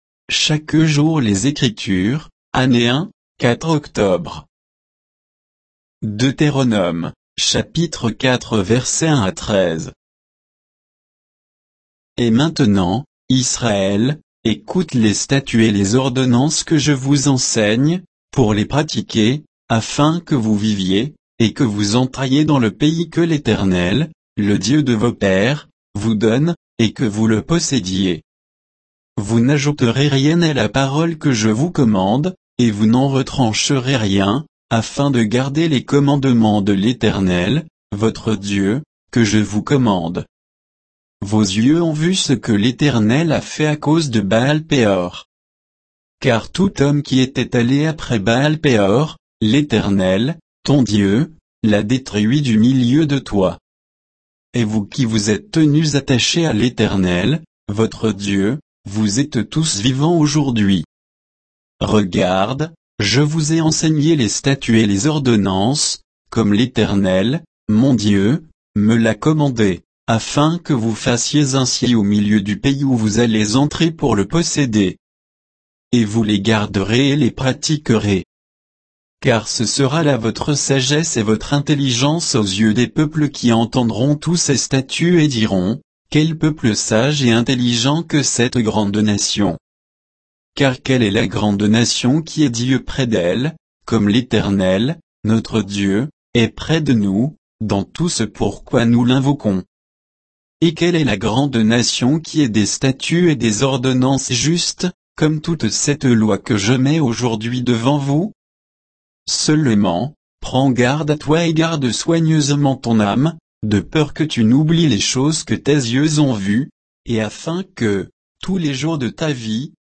Méditation quoditienne de Chaque jour les Écritures sur Deutéronome 4